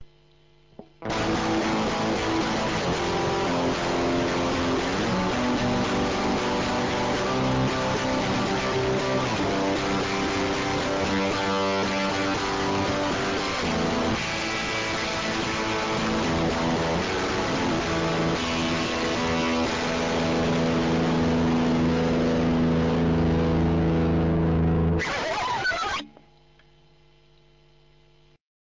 Spielweisen der  E-Gitarre
Verzerrtes offenes spielen